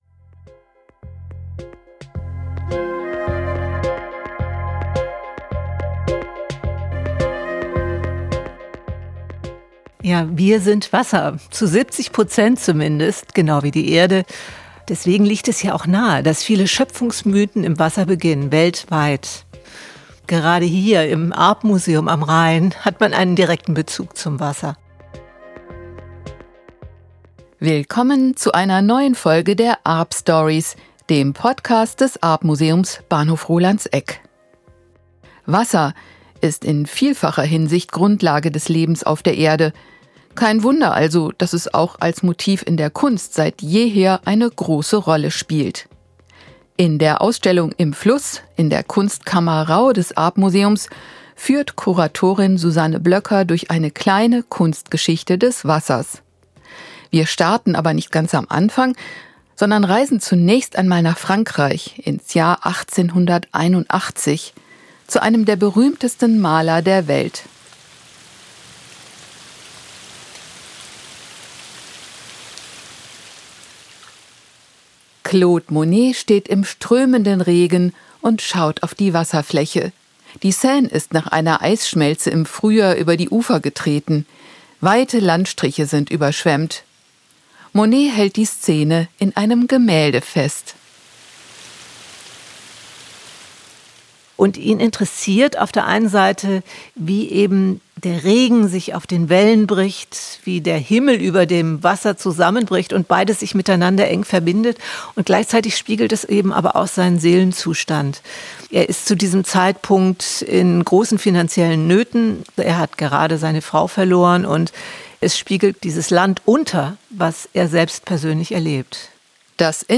Hintergründe und Interviews aus dem Arp Museum Bahnhof Rolandseck